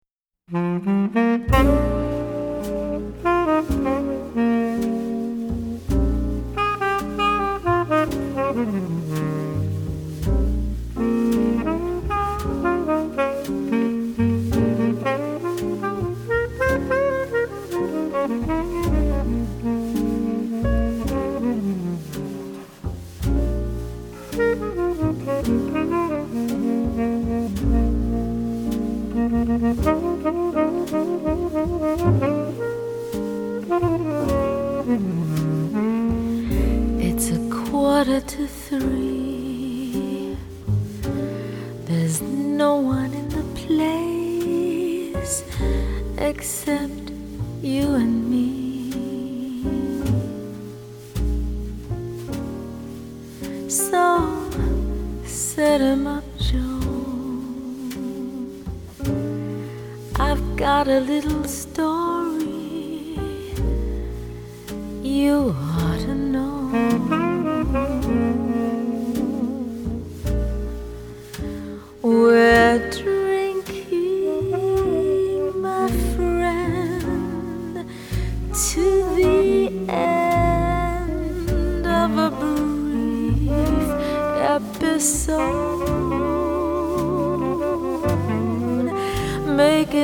＊ 國內外發燒友一致推崇最佳爵士女聲測試大碟
vocals
tenor saxophone
piano
bass
drums
guitar
trumpet
Hammond B-3 organ.